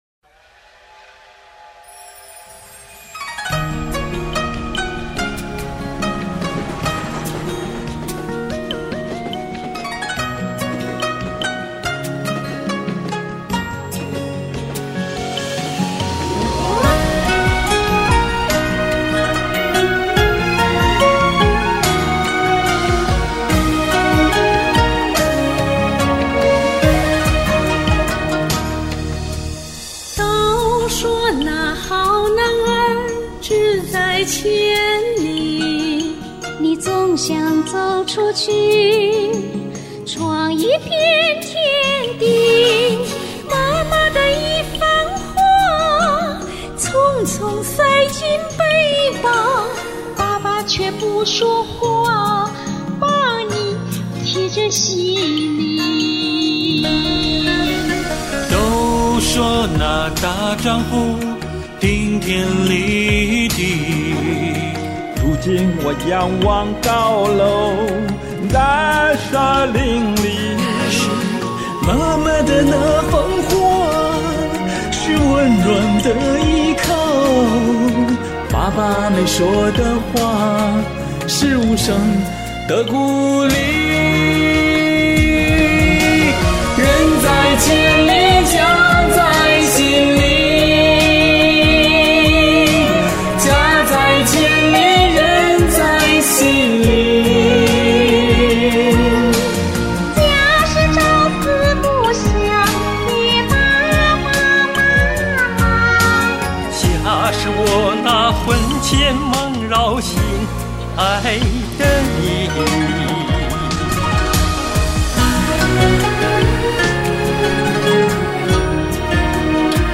原创歌曲